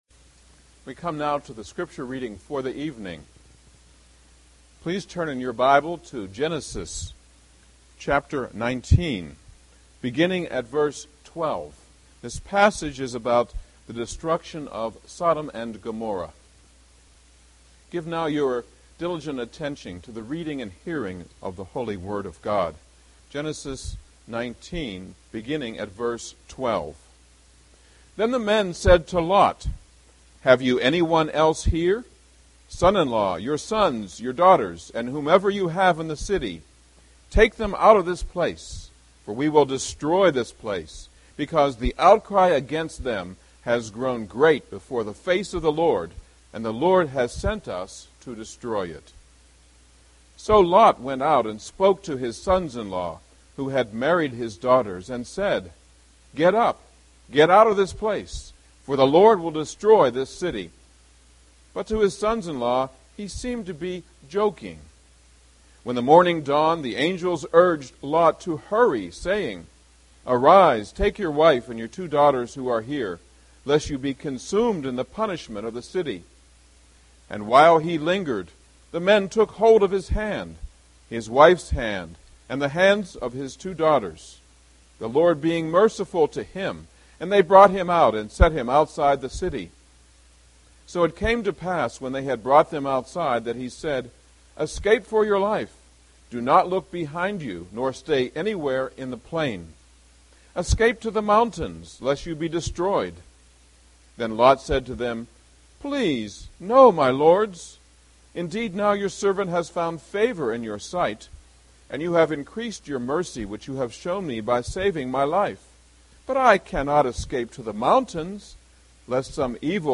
Passage: Genesis 19:12-26; Luke 17:22-32 Service Type: Sunday Evening Service